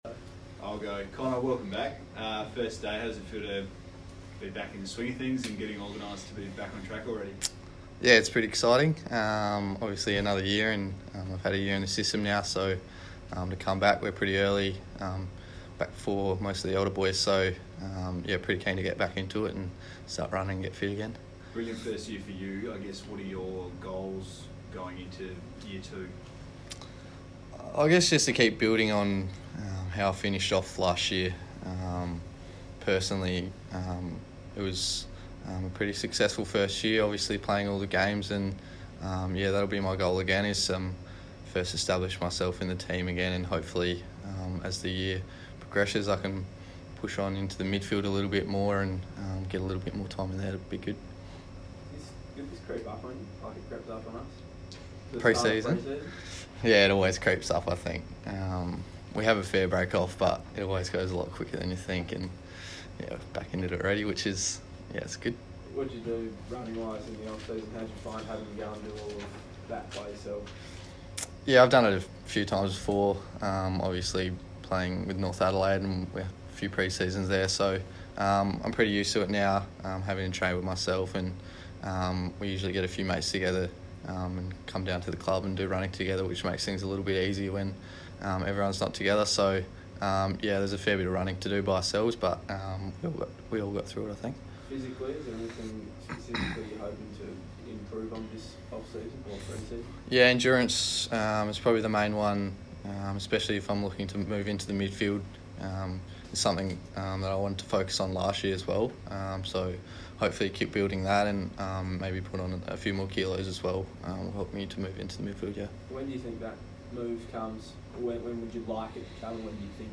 Connor Rozee press conference - Monday 4 November, 2019
Connor Rozee speaks to the media as Port Adelaide's first to fourth year players return to training.